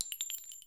Shells